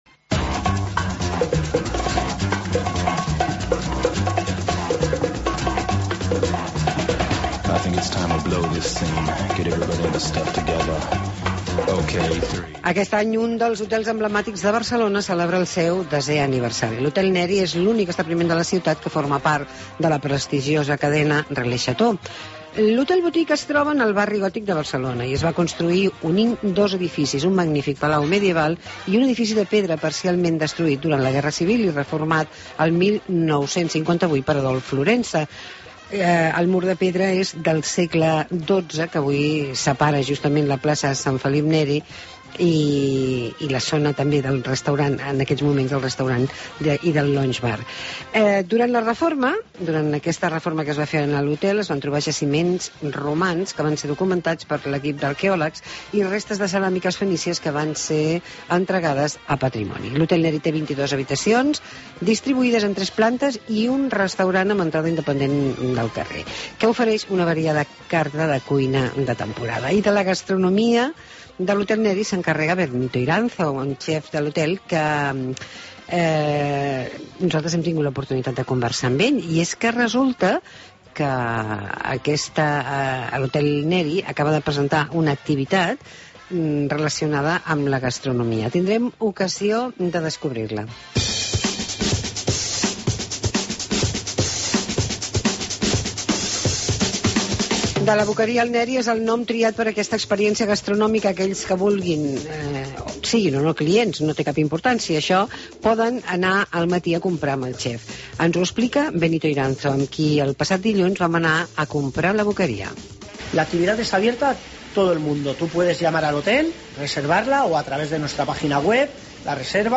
Reportatge de la Boqueria al Neri